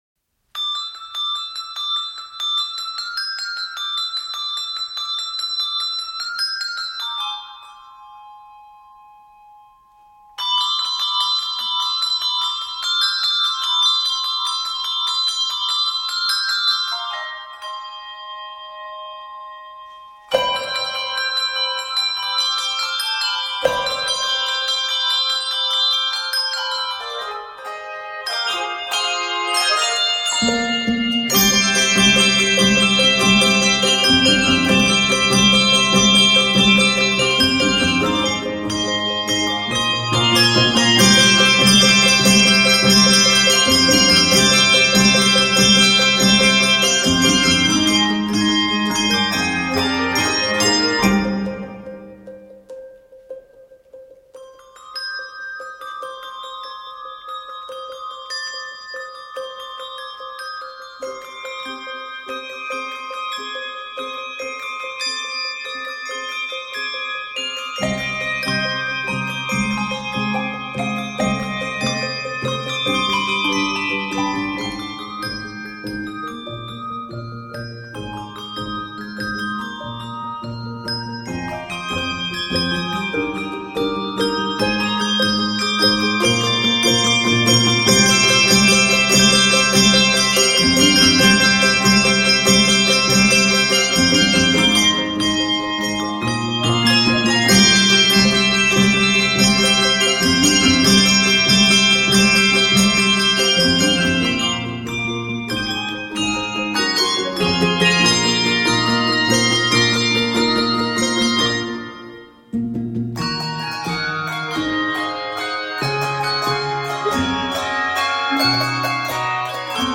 an exhilarating piece filled with joy and rhythmic adventure